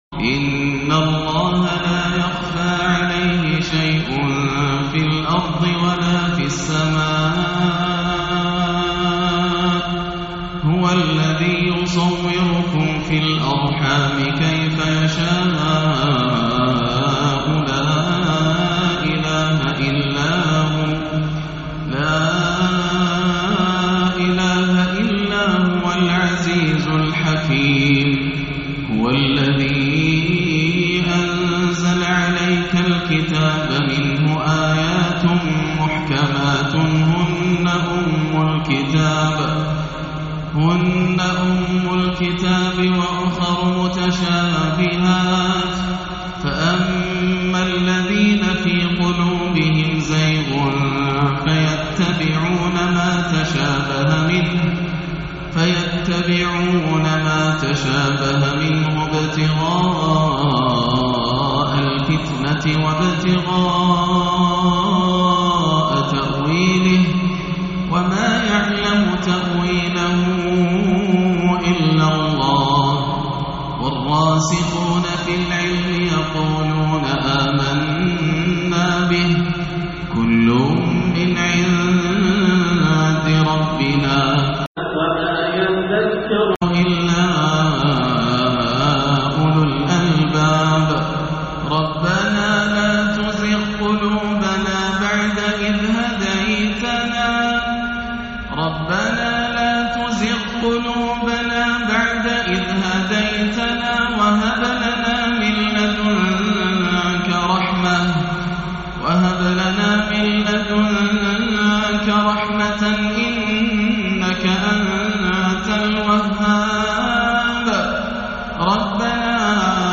(الَّذينَ يَقولونَ رَبَّنا إِنَّنا آمَنّا فَاغفِر لَنا) تلاوة نادرة باكية- عشاء 1-3-1439 > عام 1439 > الفروض - تلاوات ياسر الدوسري